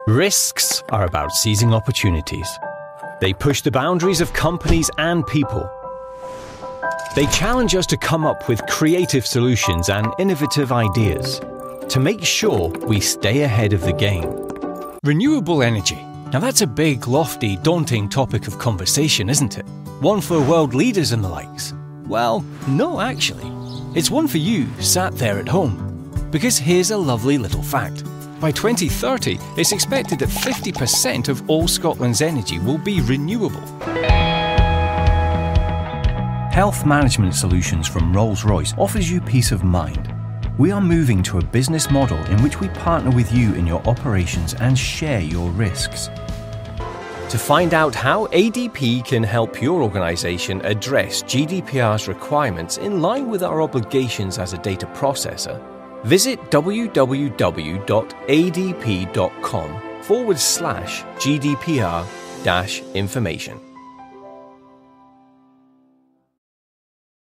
Male British voiceover.
Providing voiceovers for video and audio.
A warm, genuine British voice to tell your brands story.